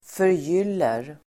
Uttal: [förj'yl:er]